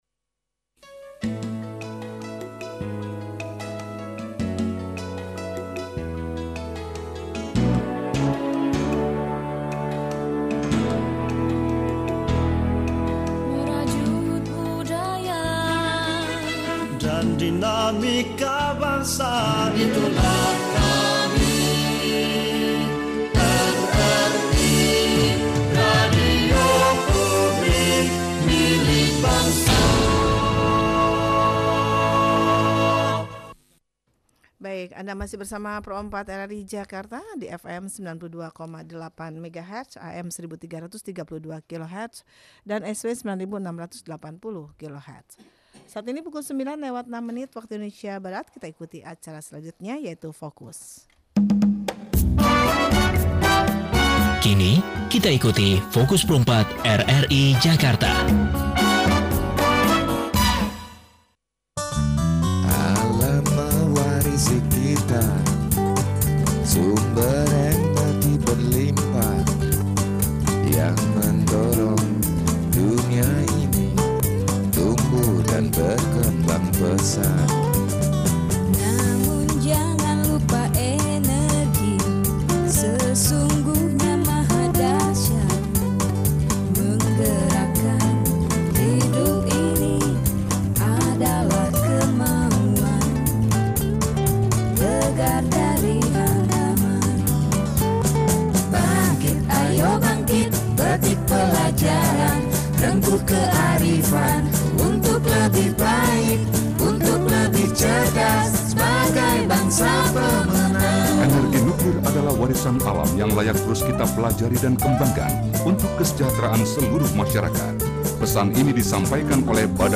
REKAM SIAR TALKSHOW BATAN DI RADIO RRI, JUDUL : PEMANFAATAN ENERGI NUKLIR DAN KESELAMATAN RADIASI - Repositori Karya